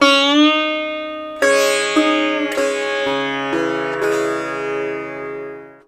SITAR LINE15.wav